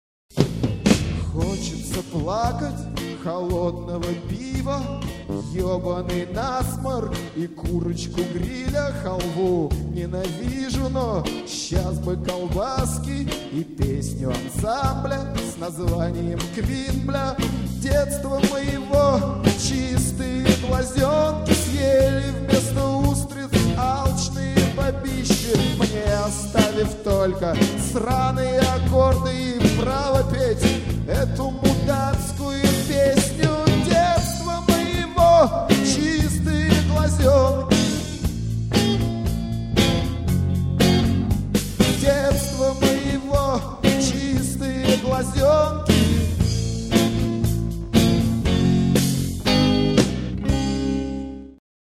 Концерт в "Отрыжке" (01.04.92)
фрагмент песни ( 52 сек.)
AUDIO, stereo